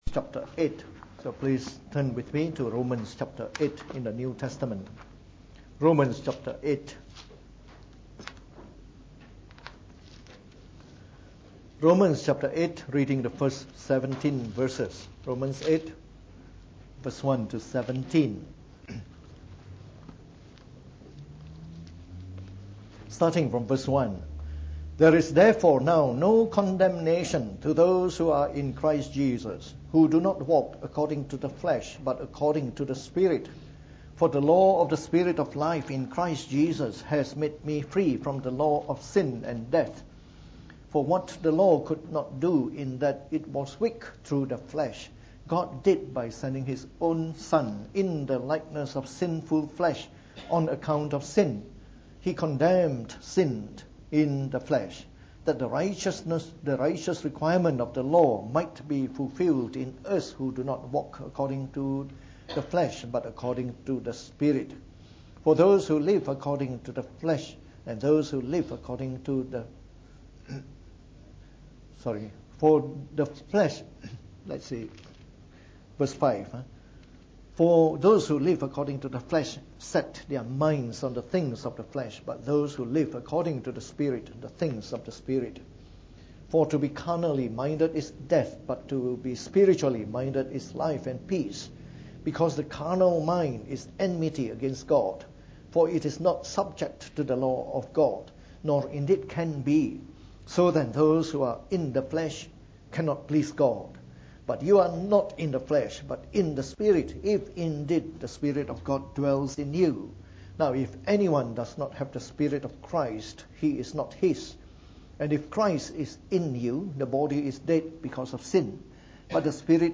From our series on the Book of Romans delivered in the Morning Service.